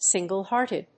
アクセントsíngle‐héarted